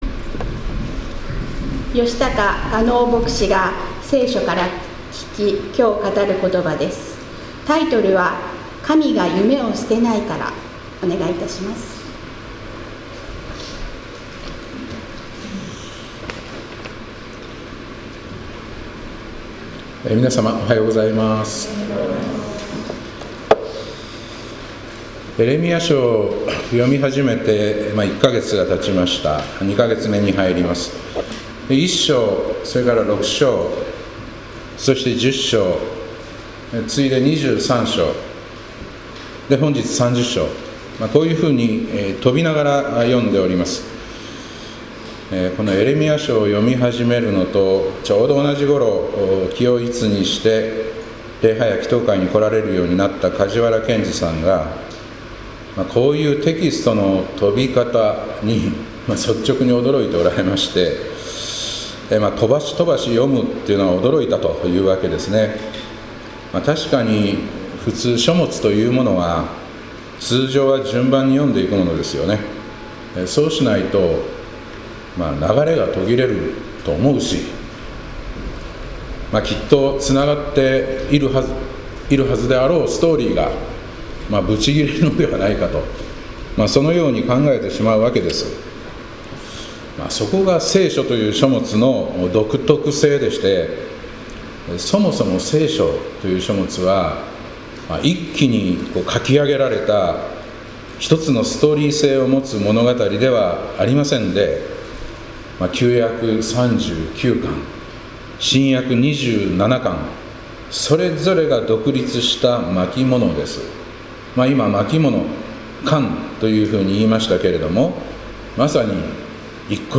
2024年11月3日礼拝「神が夢（ビジョン）を捨てないから」